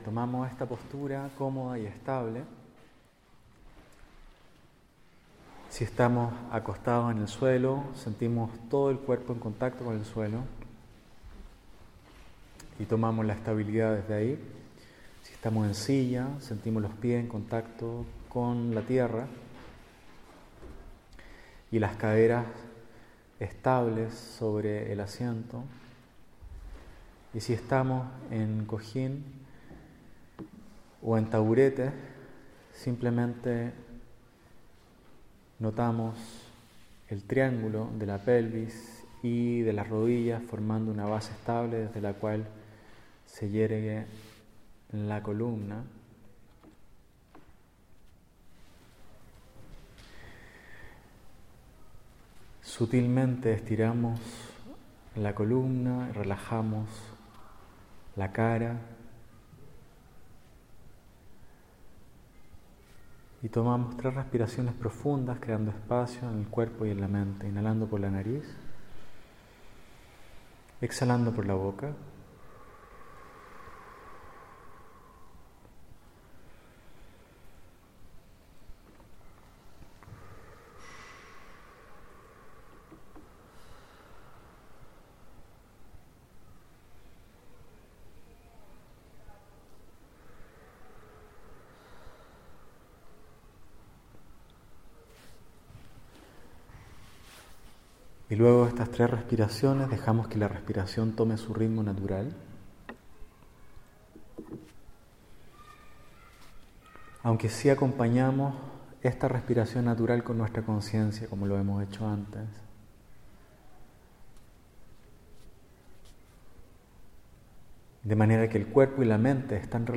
Metta-Gracias-a-la-Vida-Retiro-Viznar-Nov-2015.mp3